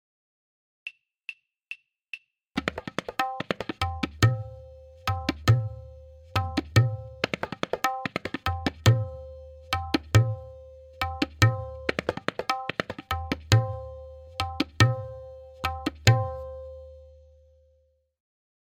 M4.2-SimpleCK-V1-Click.mp3